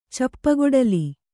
♪ cappagoḍali